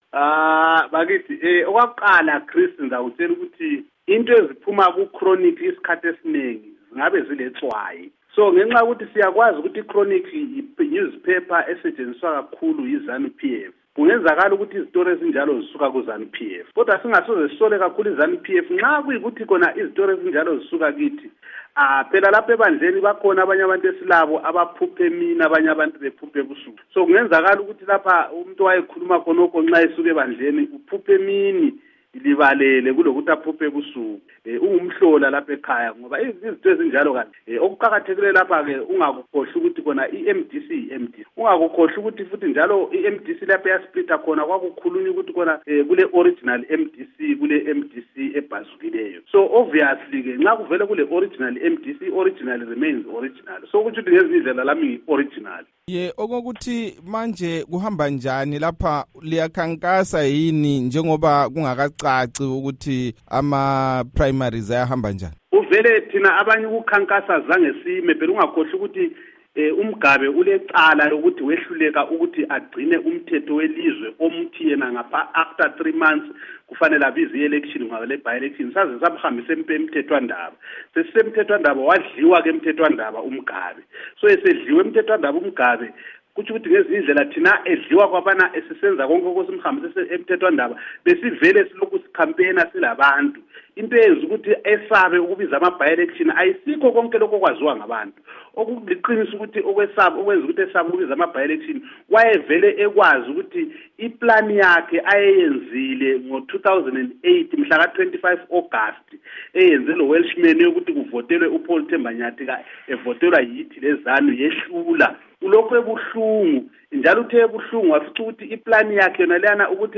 Ingxoxo loMnu. Abednico Bhebhe